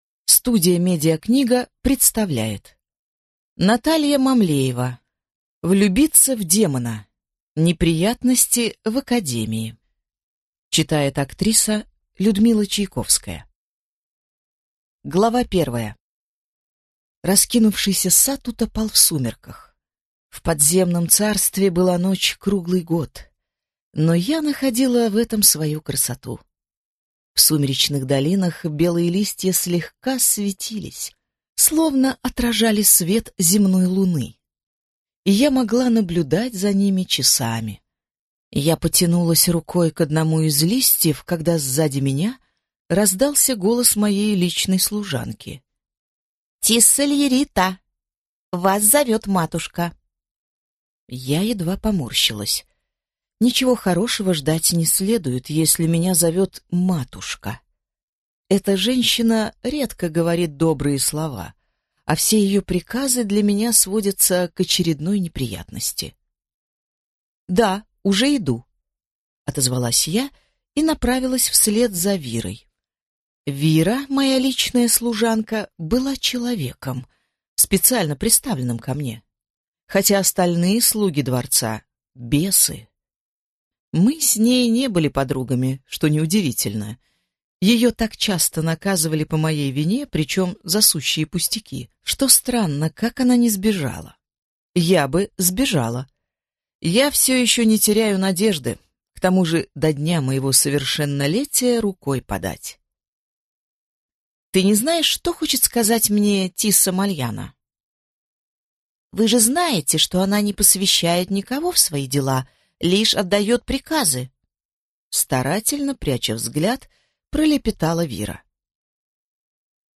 Аудиокнига Влюбиться в демона. Неприятности в академии | Библиотека аудиокниг